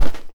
sfx_Jump.WAV